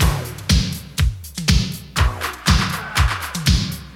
• 121 Bpm 80s Breakbeat Sample B Key.wav
Free drum loop sample - kick tuned to the B note. Loudest frequency: 1846Hz
121-bpm-80s-breakbeat-sample-b-key-USW.wav